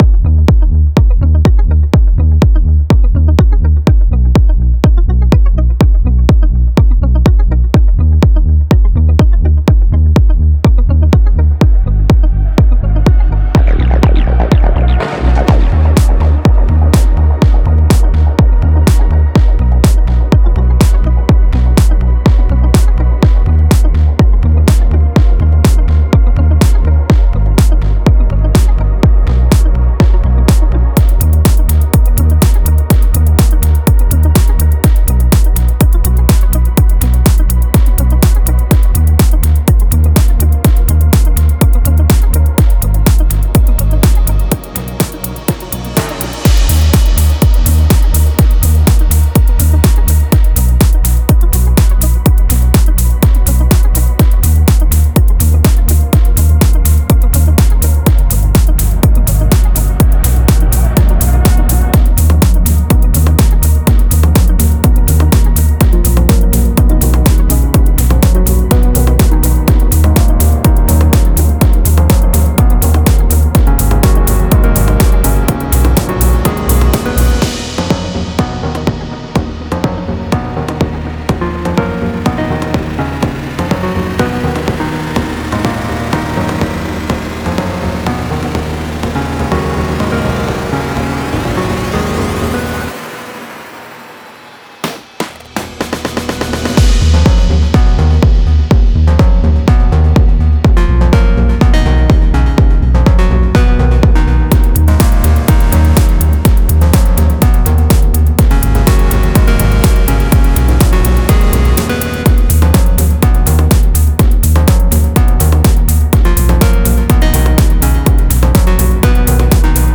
Genre: House Melodic Techno